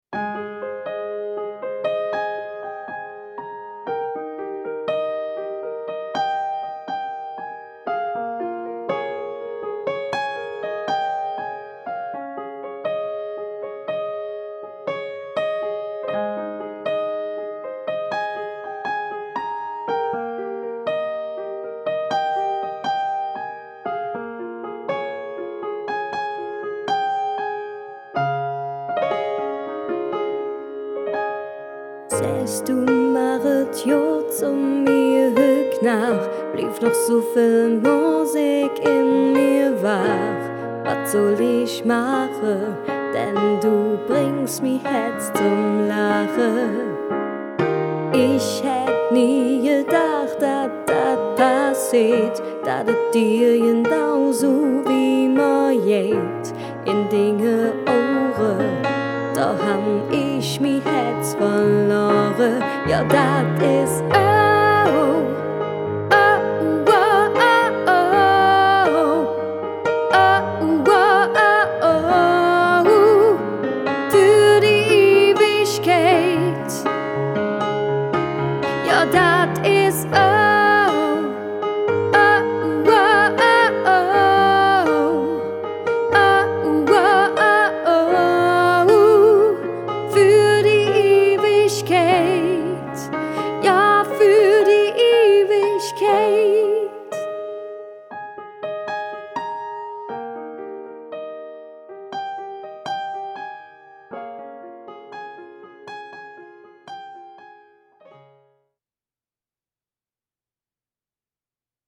Die Hochzeitssängerin op Kölsch